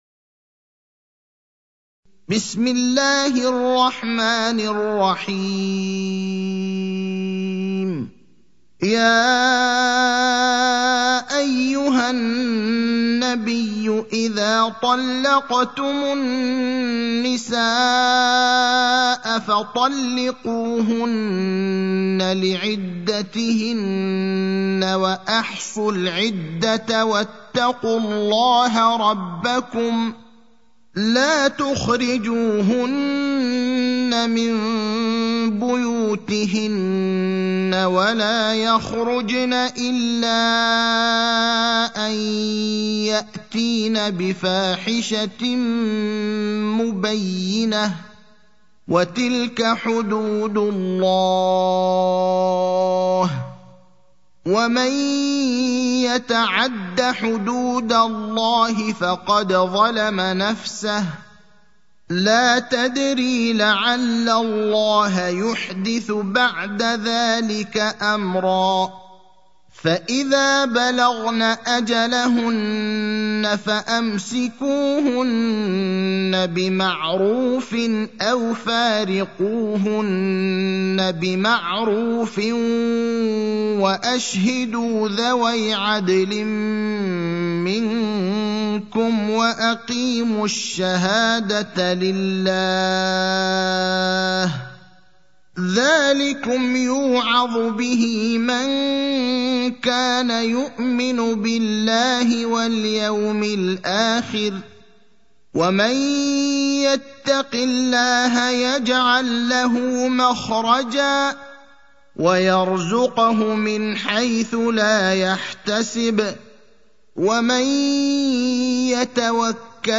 المكان: المسجد النبوي الشيخ: فضيلة الشيخ إبراهيم الأخضر فضيلة الشيخ إبراهيم الأخضر الطلاق (65)